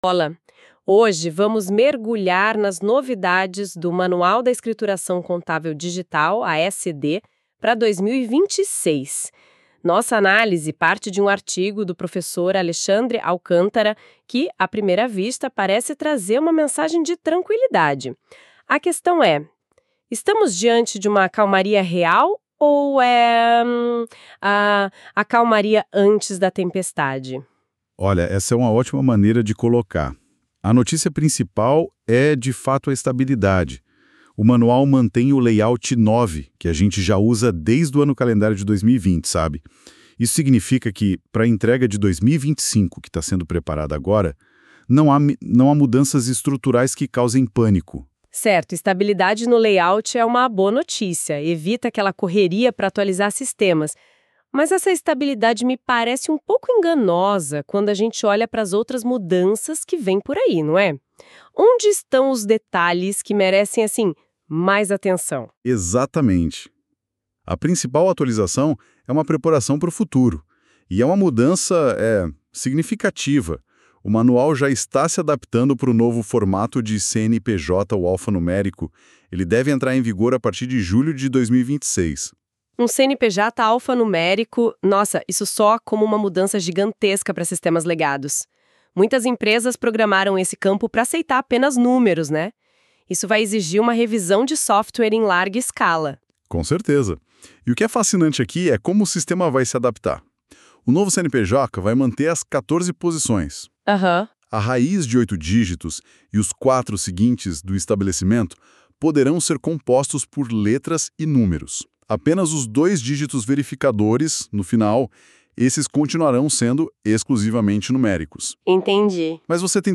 O áudio do podcast deste artigo foi gerado por IA